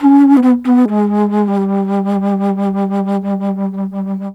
Flute 51-11.wav